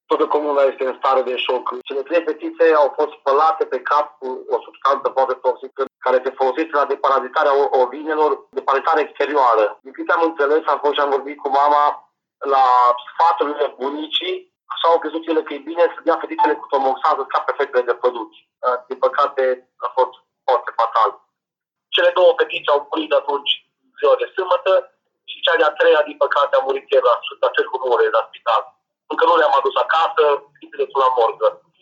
Localnicii sunt în stare de șoc, a spus la Europa FM, primarul Mircea Silviu Șchiopu.